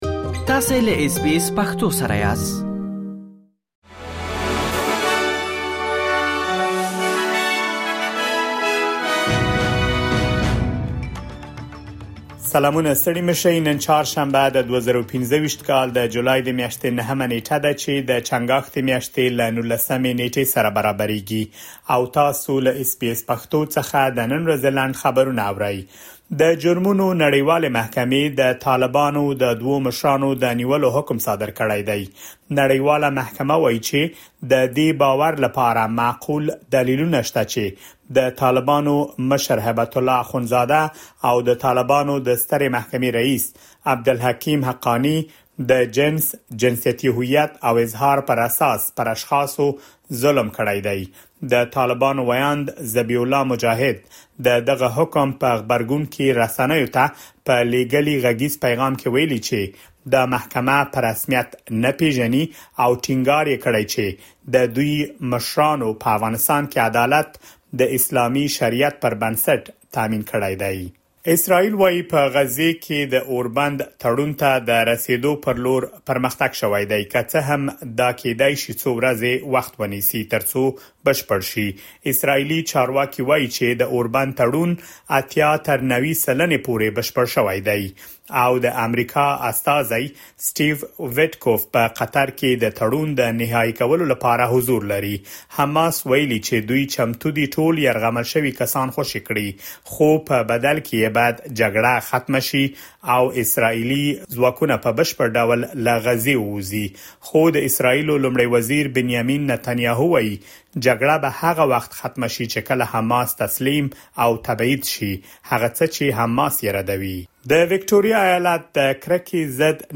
د اس بي اس پښتو د نن ورځې لنډ خبرونه |۹ جولای ۲۰۲۵